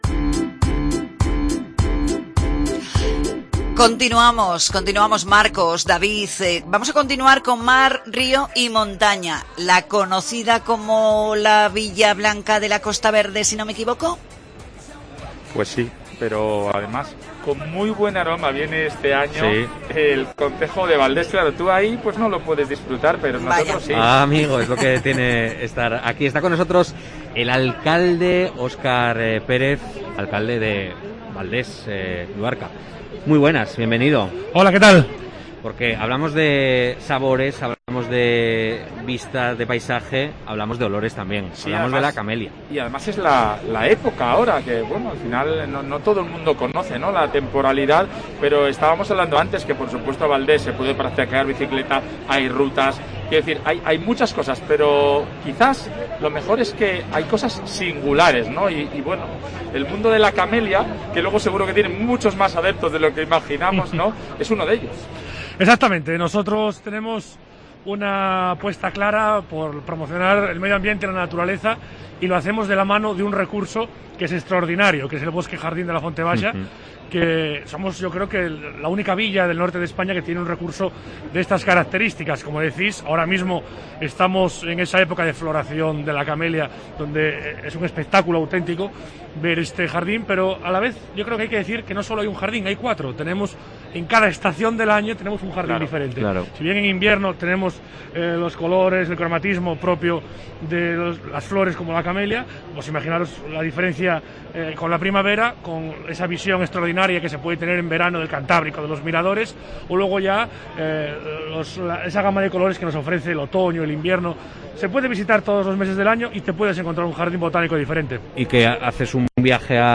El alcalde del concejo occidental asturiano, Óscar Pérez, ha estado en el especial de COPE Asturias desde IFEMA Madrid con motivo de la Feria Internacional del Turismo
Fitur 2022: Entrevista a Óscar Pérez, alcalde de Valdés